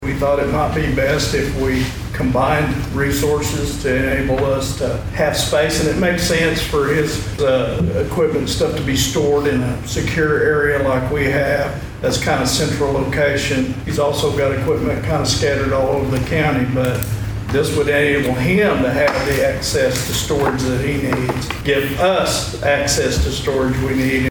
At Monday's Osage County Commissioners meeting, it was brought to the Board's attention as to the poor conditions the evidence room is in at the sheriff's office.